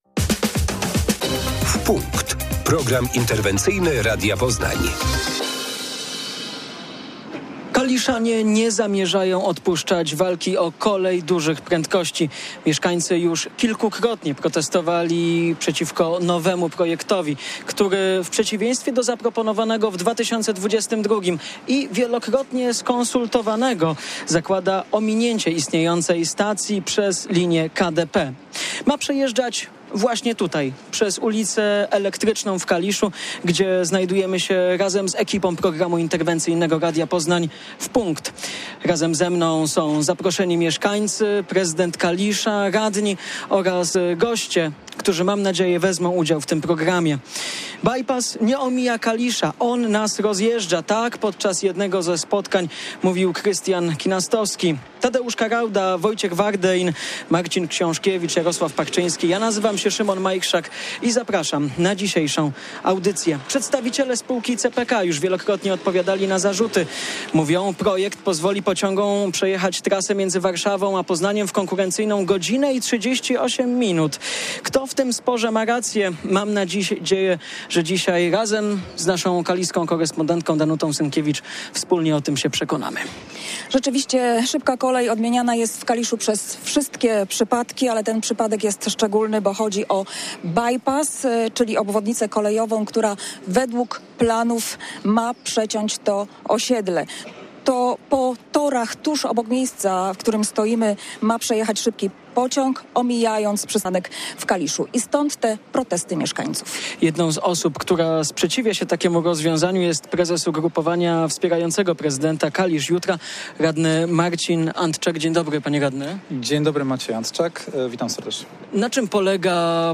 Prezydent, radni różnych ugrupowań i mieszkańcy Kalisza wspólnie protestowali przeciwko trasie szybkiej kolei. Swój sprzeciw podczas audycji Radia Poznań „W Punkt” wyraziło prawie 200 osób.